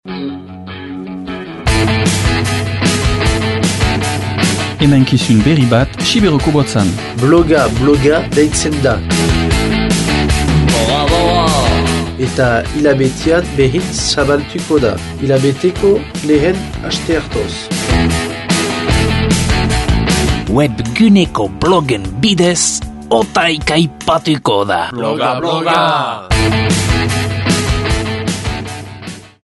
Soinü deia entzün :